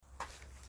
DungeonsOfKharadum/src/assets/audio/sfx/walk/dirt/step5.mp3 at 4ec52c34d49a619098eca7e4dd09aace7b8113ba